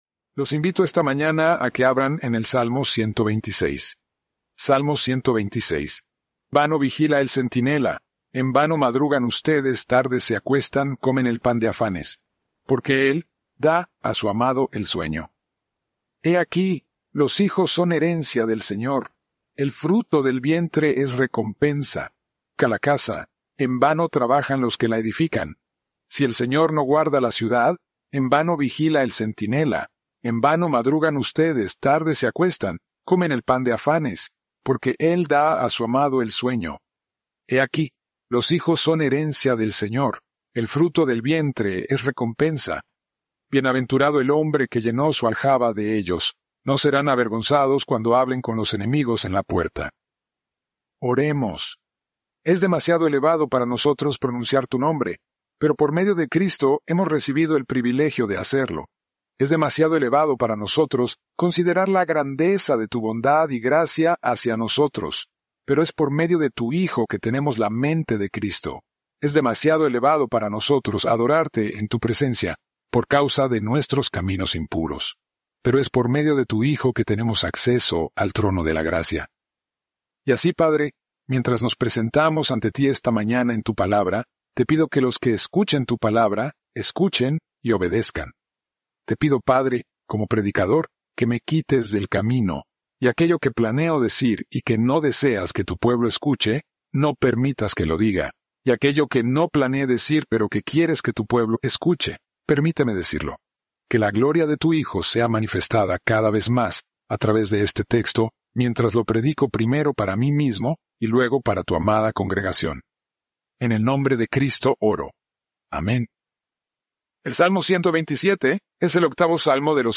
Preached January 4, 2026 from Salmo 127